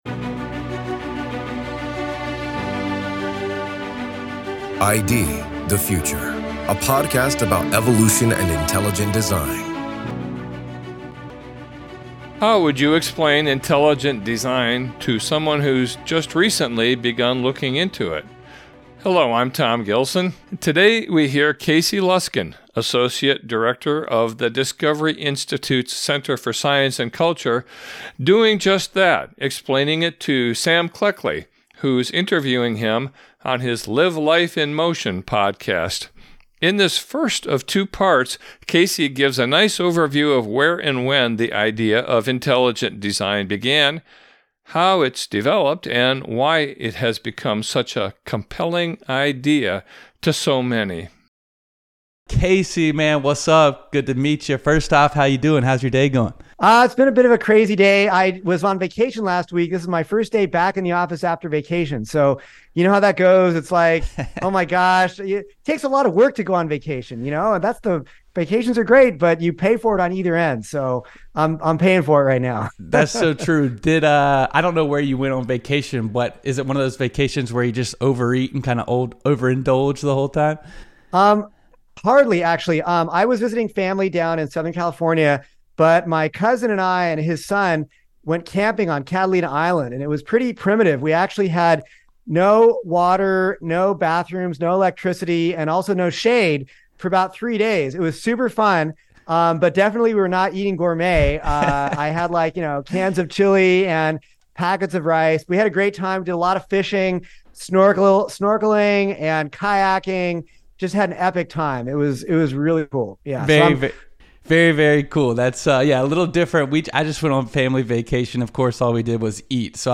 This is Part 1 of a two-part discussion.